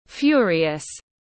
Điên tiết tiếng anh gọi là furious, phiên âm tiếng anh đọc là /ˈfjʊriəs/
Furious /ˈfjʊriəs/